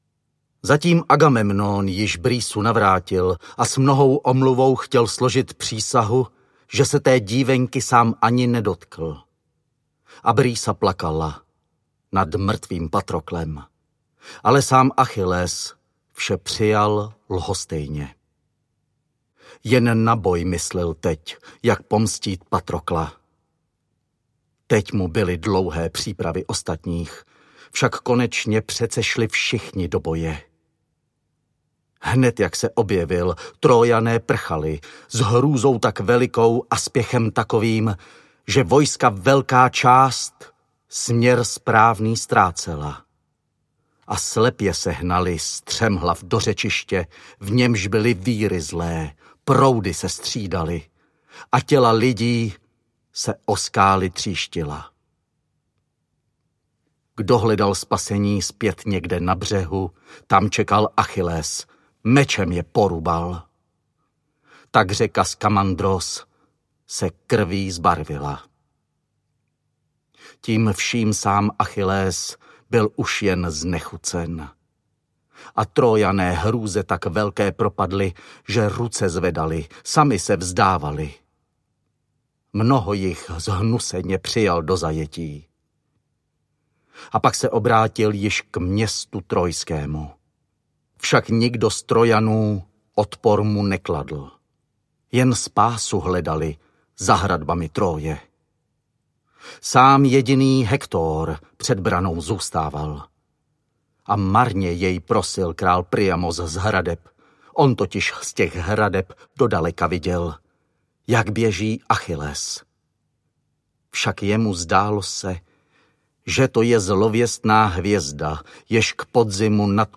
Achilleus audiokniha
Ukázka z knihy
Vyrobilo studio Soundguru.